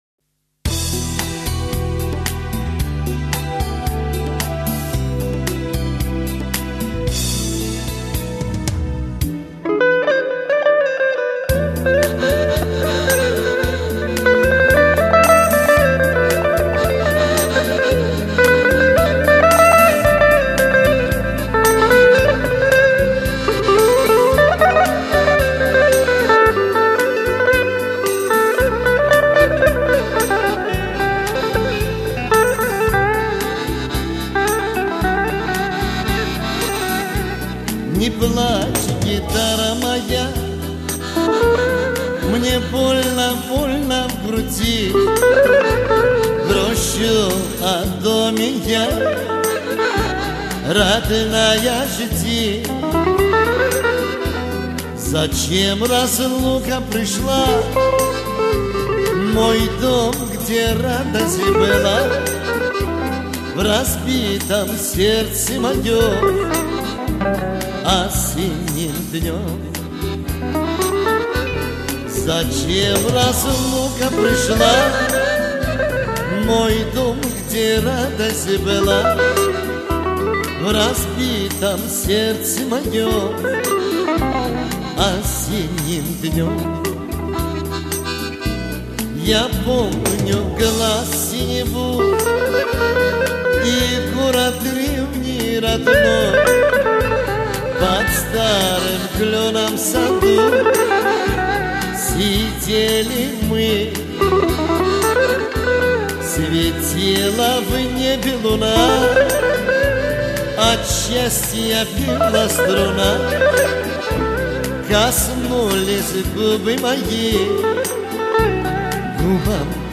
восточный вариант